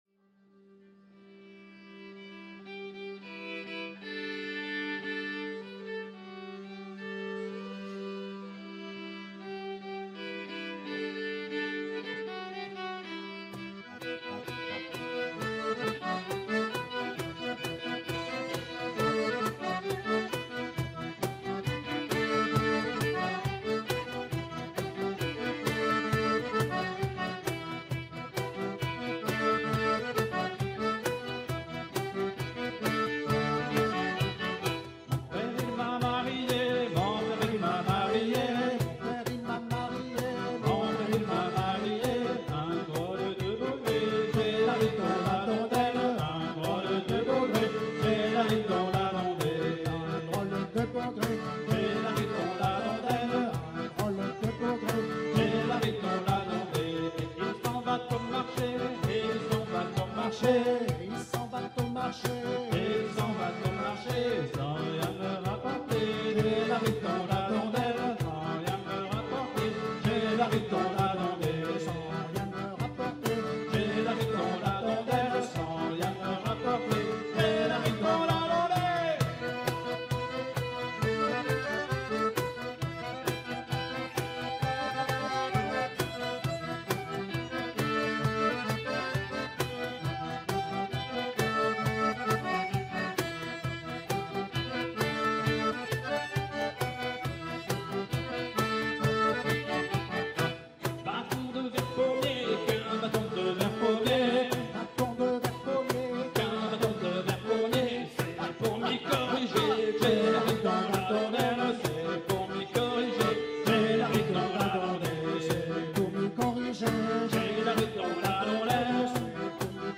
Laridé 8 temps